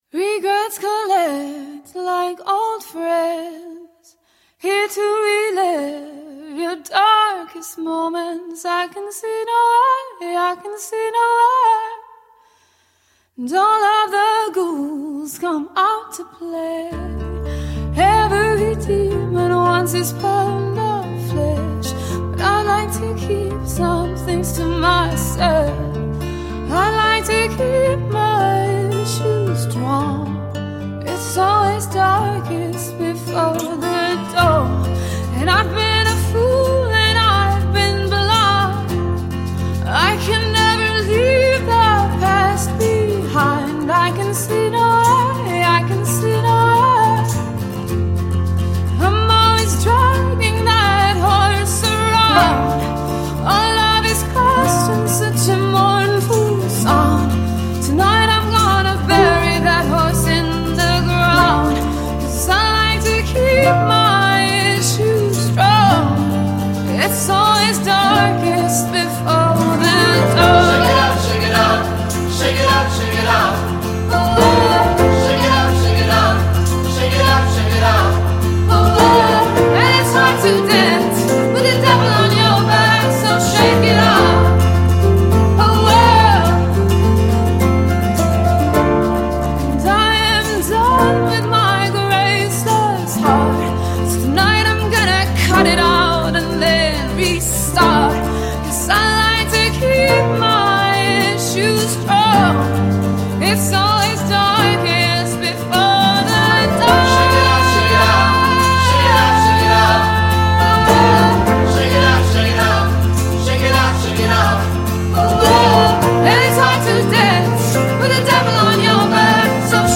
an acoustic take
Things build beautifully from there.